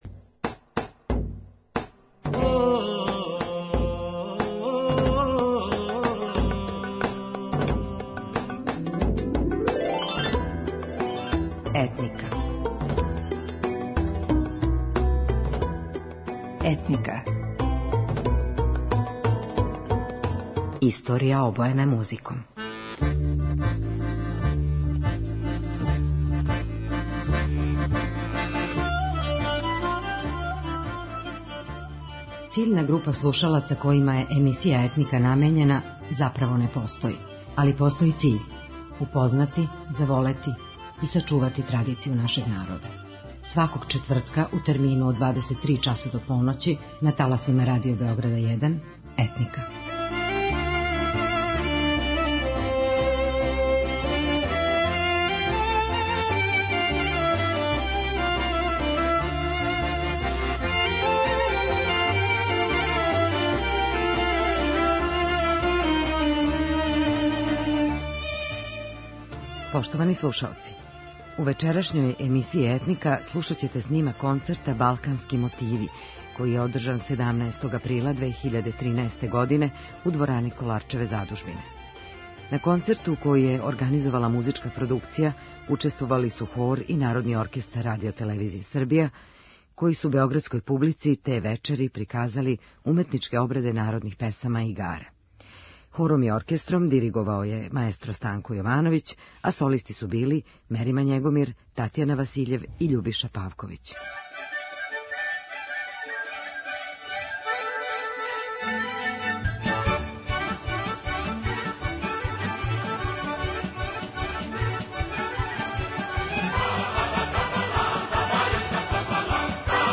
У вечерашњој емисији слушаћете уметничке обраде народних песама и игара у извођењу хора и Народног оркестра РТС-а.